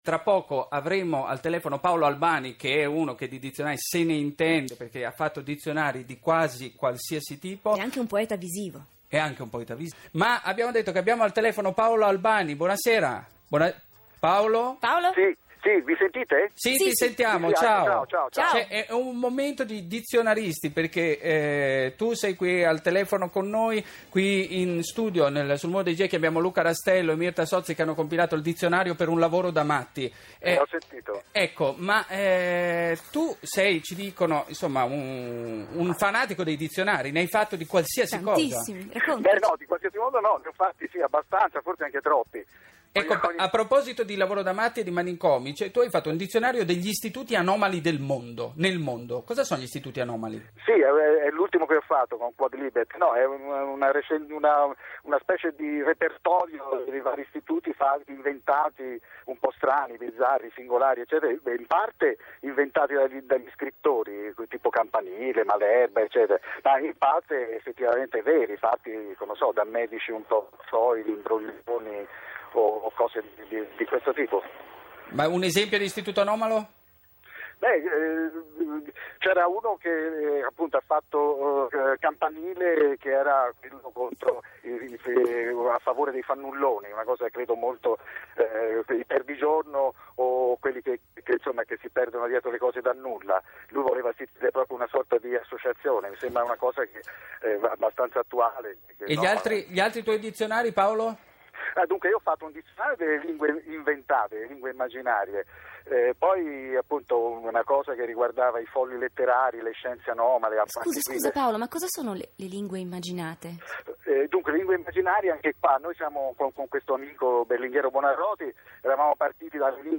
Il Geco di città, trasmissione di RaiRadio2, condotta da Andrea Bajani, del 12 agosto 2010.